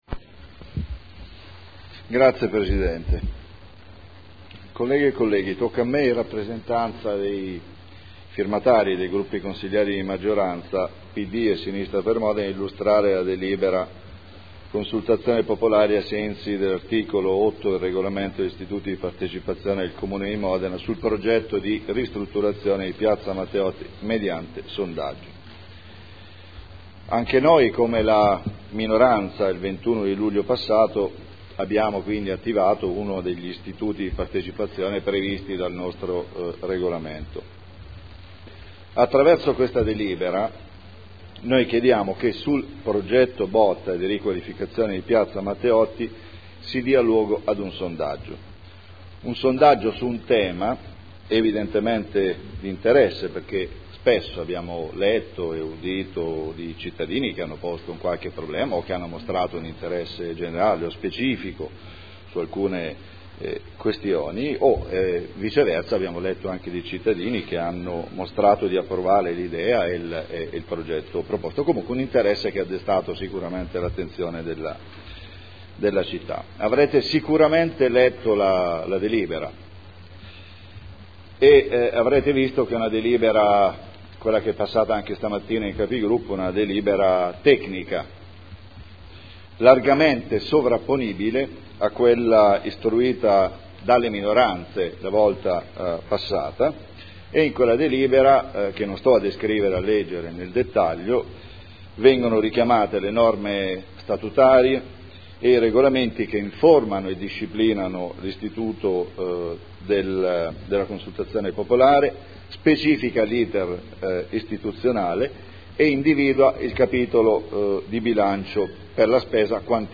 Paolo Trande — Sito Audio Consiglio Comunale
Seduta del 05/09/2011. Consultazione popolare ai sensi dell’art. 8 del Regolamento degli Istituti di Partecipazione del Comune di Modena sul progetto di ristrutturazione di Piazza Matteotti mediante sondaggio (Conferenza Capigruppo del 5 settembre 2011)